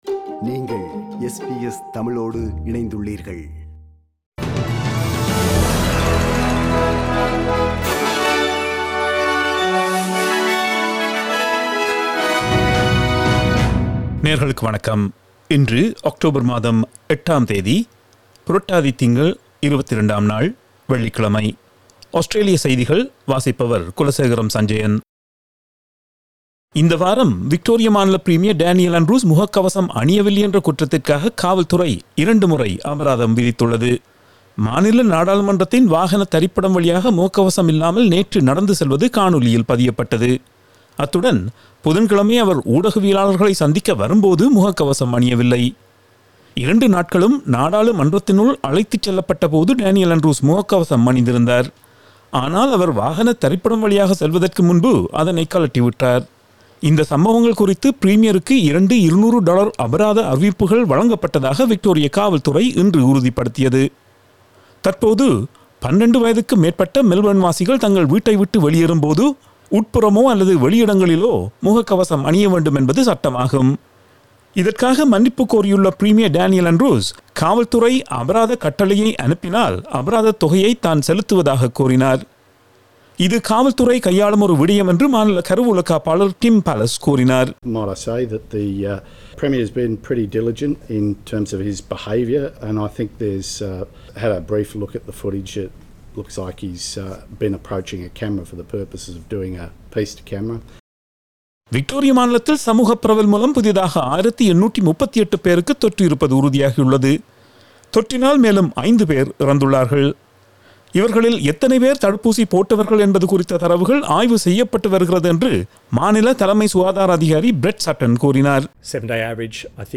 Australian news bulletin for Friday 08 October 2021.